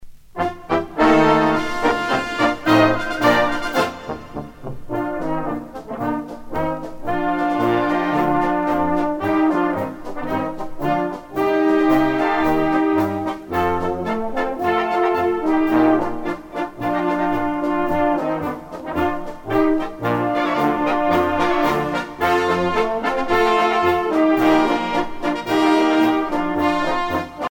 danse : polka